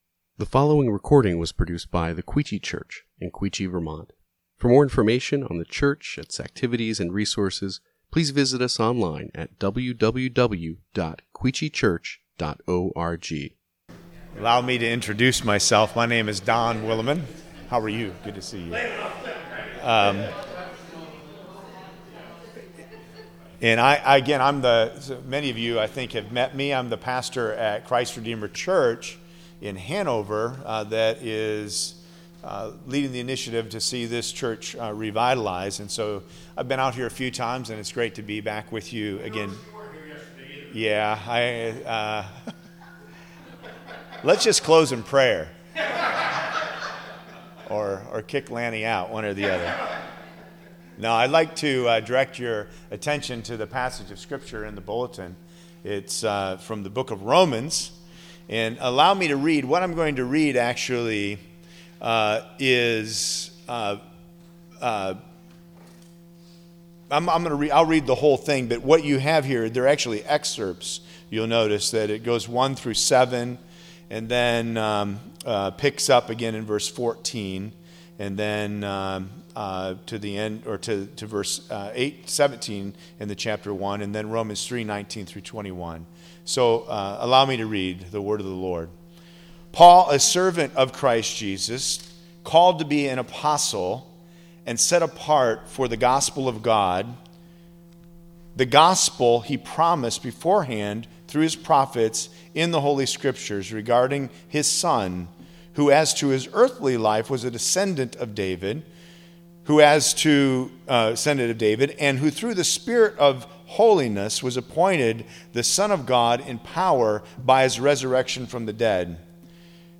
Sermon Passage